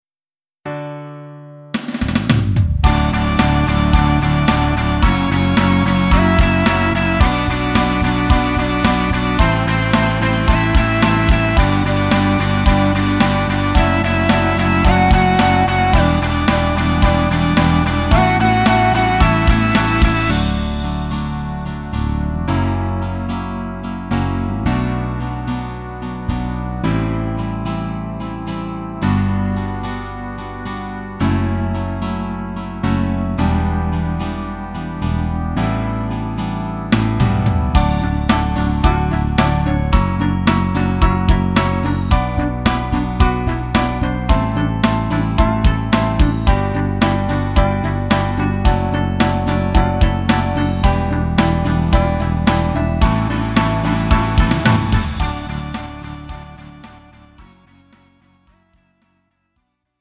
음정 -1키 3:57
장르 가요 구분 Lite MR